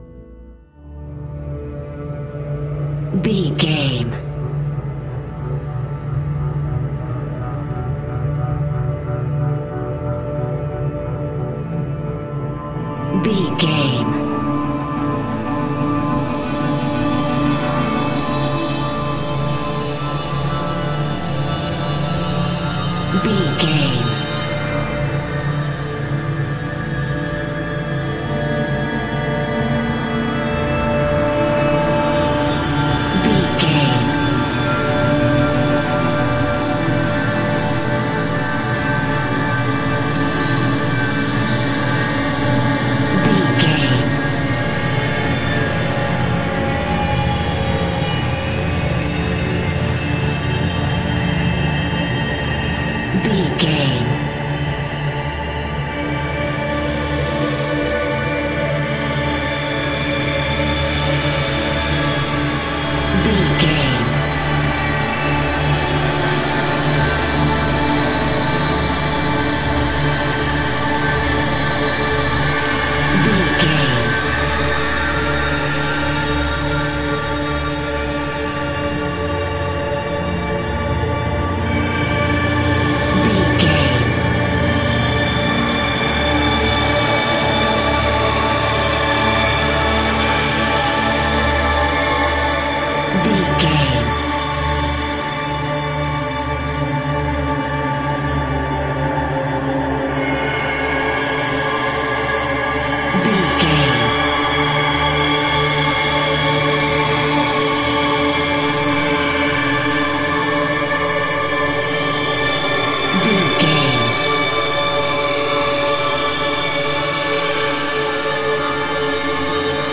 Thriller Music In The Background.
Aeolian/Minor
Slow
tension
ominous
dark
haunting
eerie
synthesizer
ambience
pads